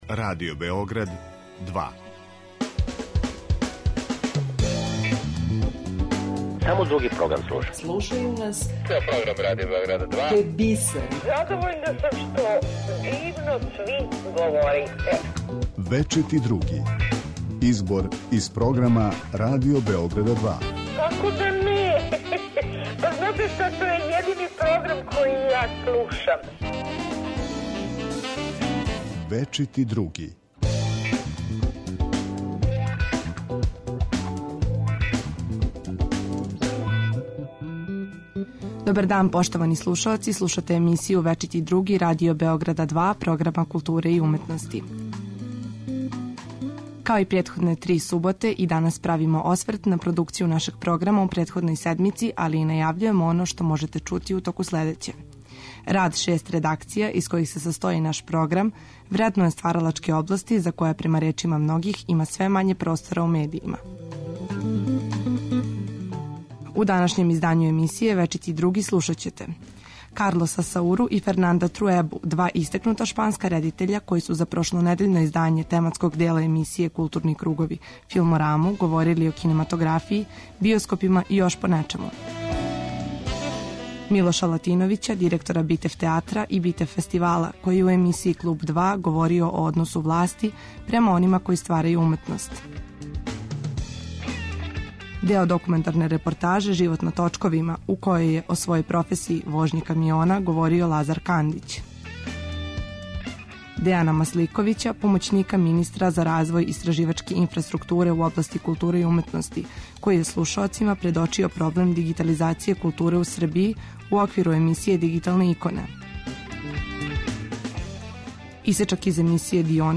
У емисији „Вечити други“ слушаоци ће моћи да чују избор најзанимљивијих садржаја емитованих на програму Радио Београда 2 током претходне и најаву онога што ће бити на програму идуће седмице.